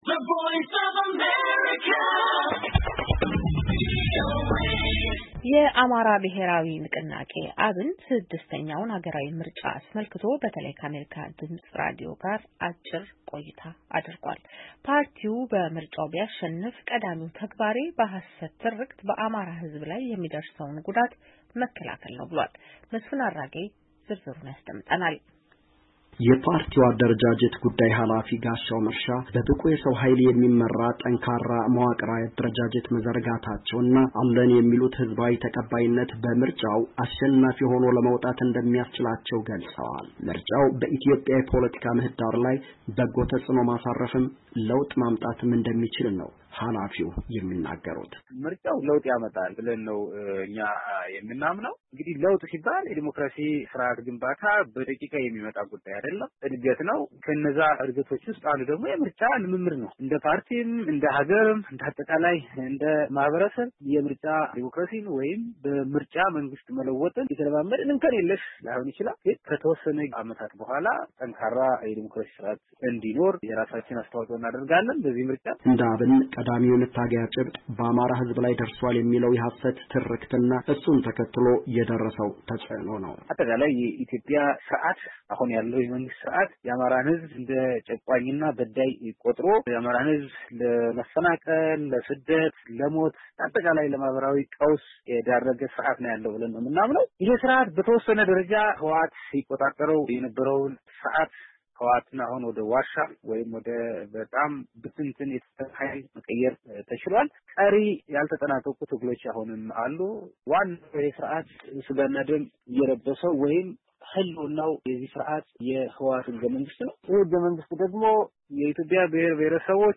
የአማራ ብሔራዊ ንቅናቄ /አብን/ ስድስተኛውን ሀገራዊ ምርጫ አስመልክቶ በተለይ ከአሜሪካ ድምፅ ራዲዮ ጋር አጭር ቆይታ አድርጓል፡፡